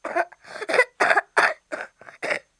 小孩子沙哑咳嗦音效_人物音效音效配乐_免费素材下载_提案神器
小孩子沙哑咳嗦音效免费音频素材下载